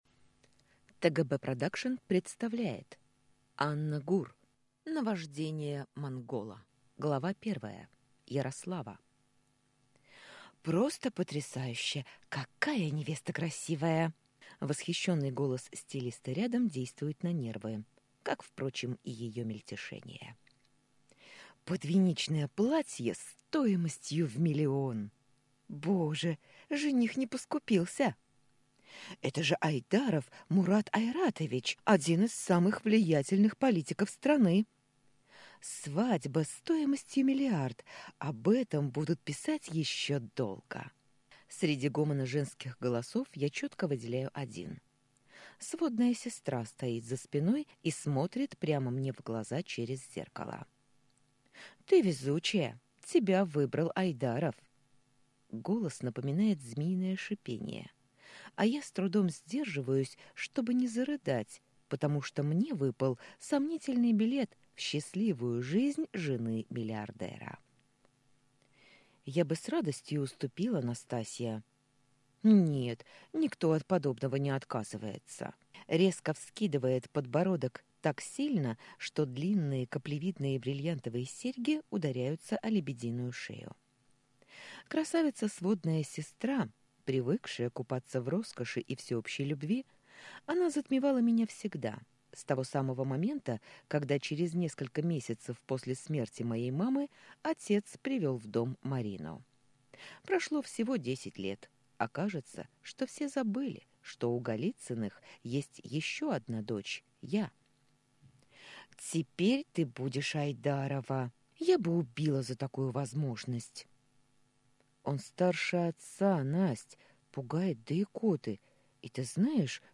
Аудиокнига Наваждение Монгола | Библиотека аудиокниг